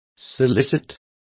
Complete with pronunciation of the translation of soliciting.